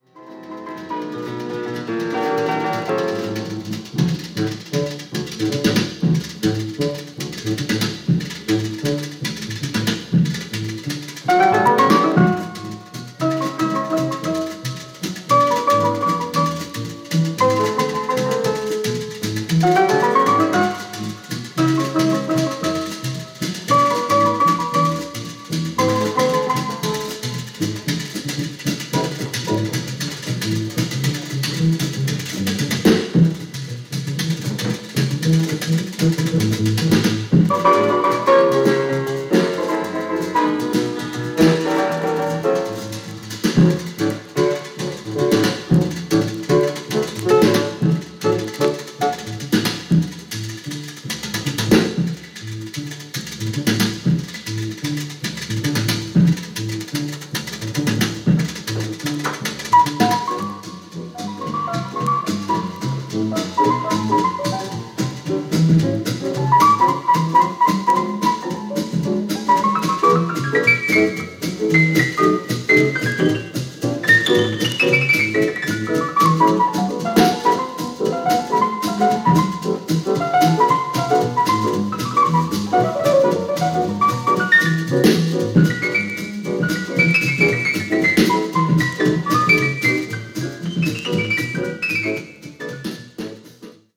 Japanese Mono盤